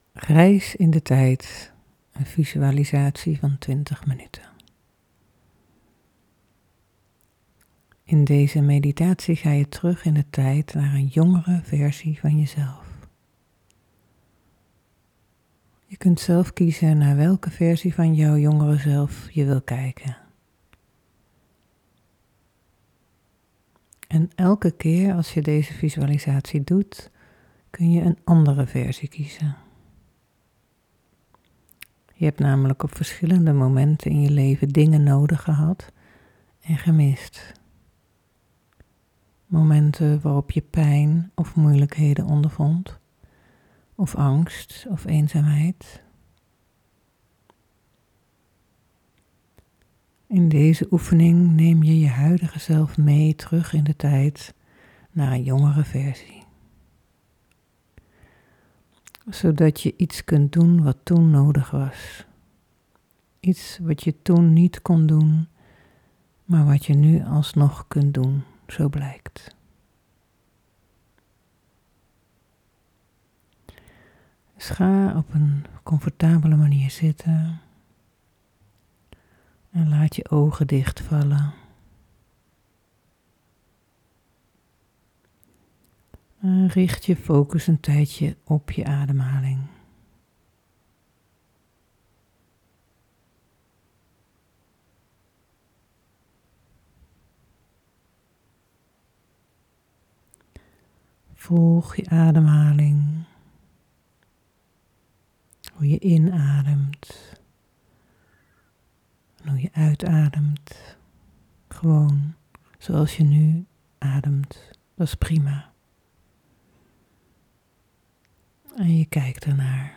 Time Traveler Visualization Meditation